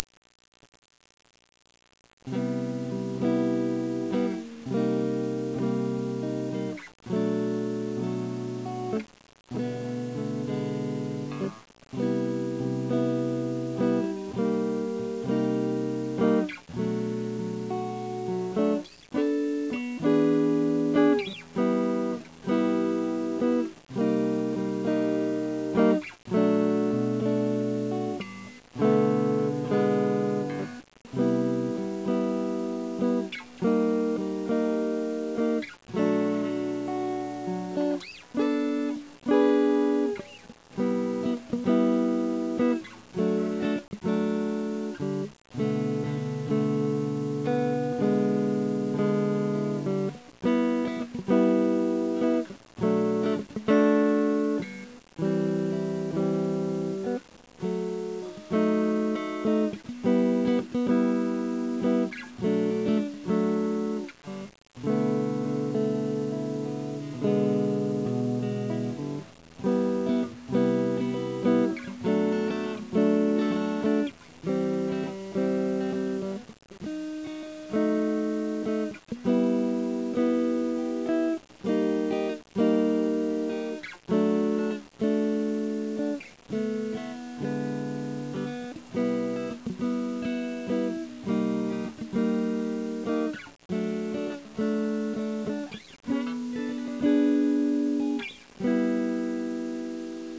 これベースリフなんだけど何気にスラップができるようになって